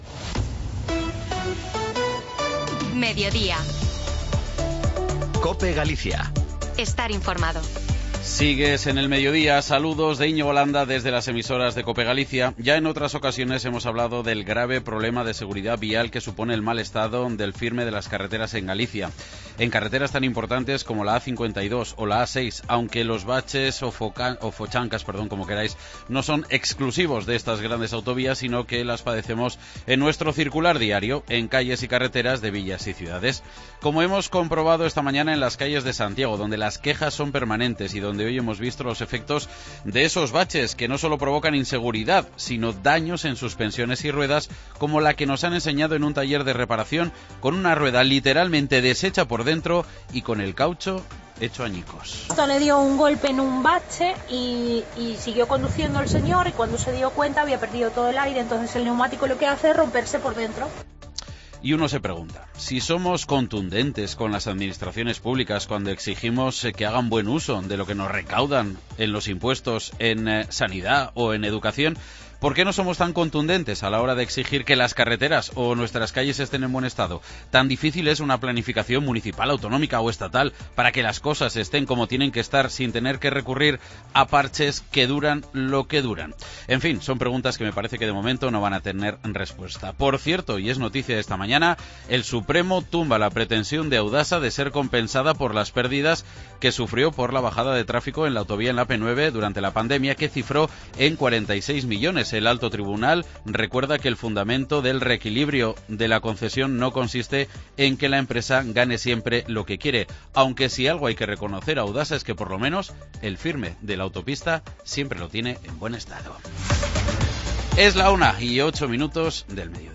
Hablamos con el Director Xeral de Comercio de la Xunta de Galicia, Manuel Heredia, ante la próxima salida de los Bonos Activa Comercio que a través de ayudas a la compra, han permitido los últimos años movilizar 65 millones de euros en los 27.000 establecimientos comerciales que se hana dherido a la iniciativa.